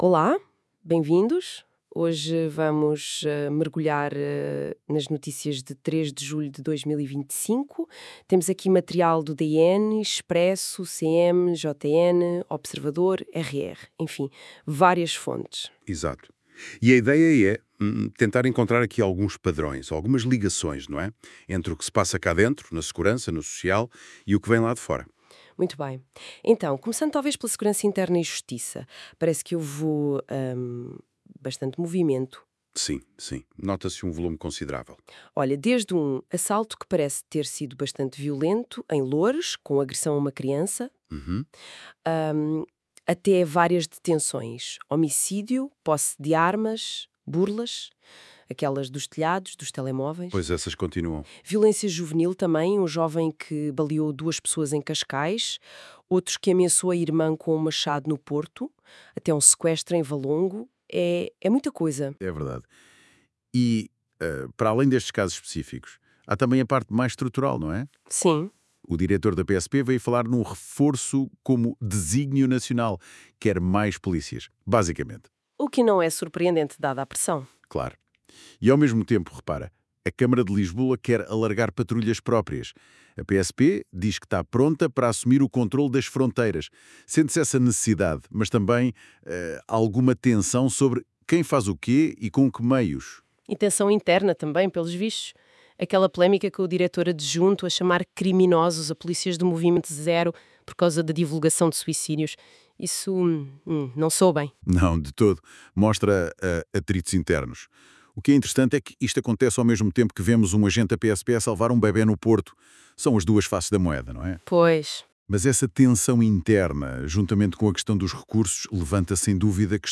Resumo áudio